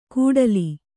♪ kūḍali